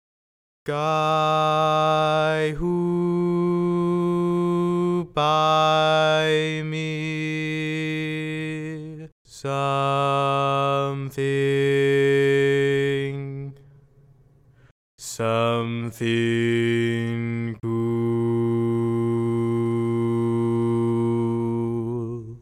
Key written in: E♭ Major
Each recording below is single part only.